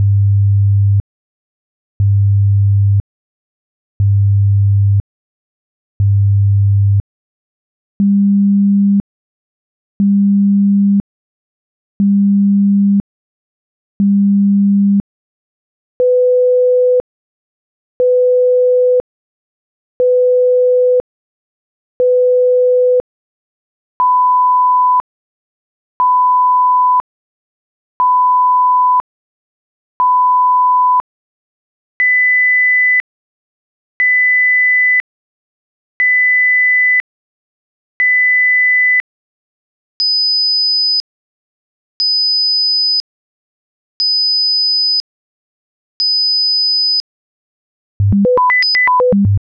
Atlas - STest1-Pitch-Left-100,200,500,1000,2000,5000.wav